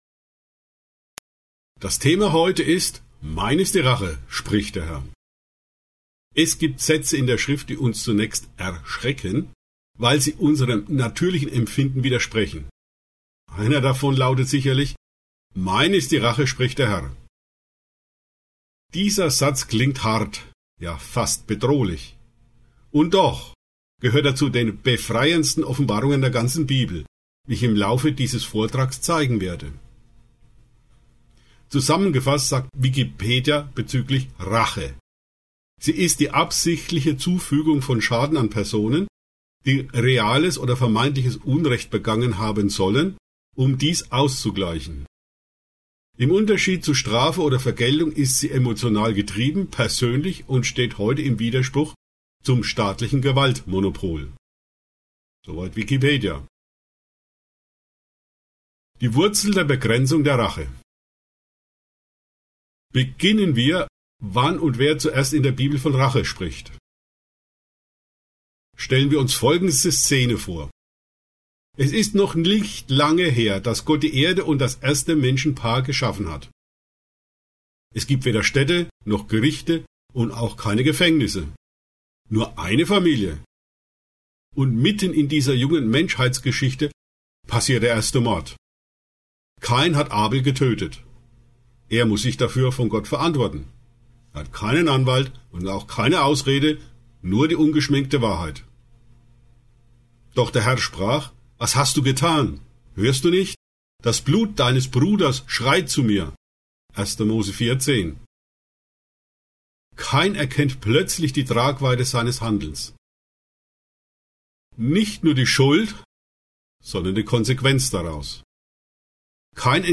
Wenn dich dieser Vortrag gestärkt hat, abonniere den Kanal und teile ihn weiter.